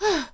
peach_panting.ogg